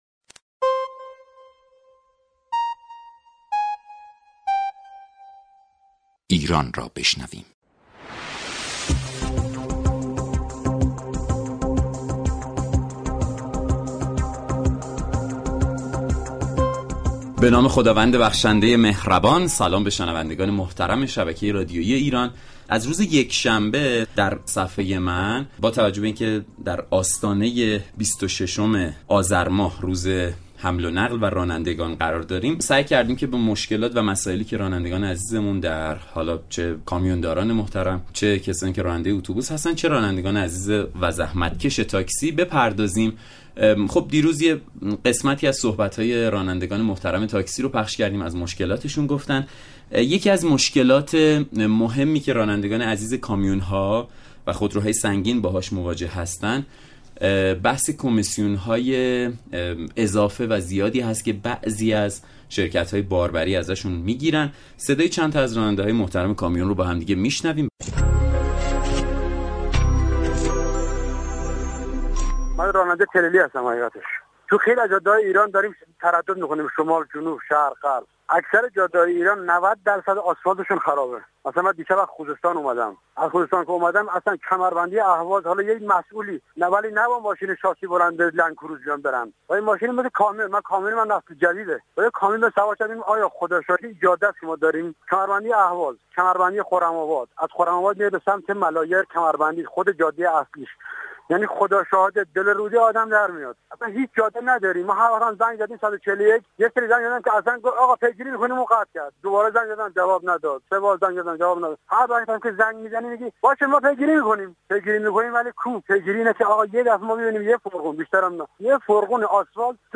عباسعلی بنی اسدی مدیر كل دفتر حقوقی سازمان راهداری كشور در گفت و گو با «صفحه من» درباره اخذ كمیسیون اضافی شركت های باربری از رانندگان صحبت كرد.